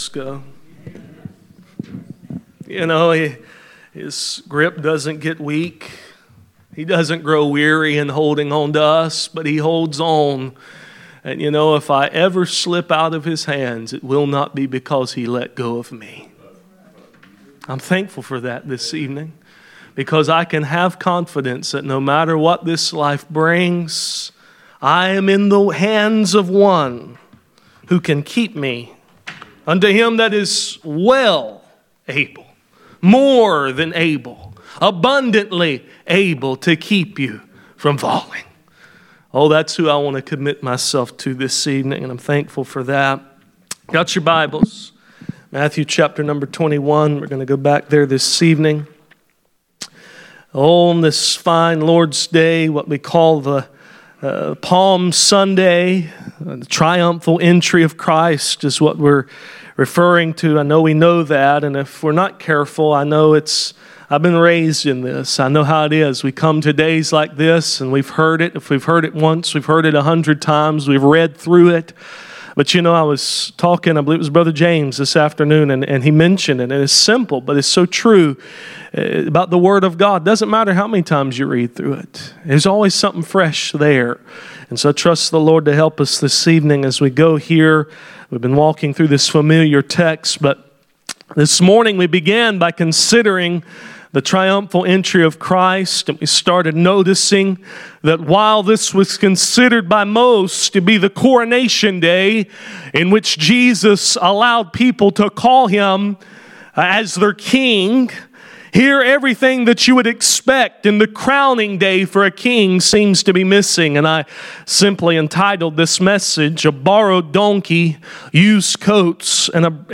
Passage: Matthew 21:1-11 Service Type: Sunday Evening « A borrowed donkey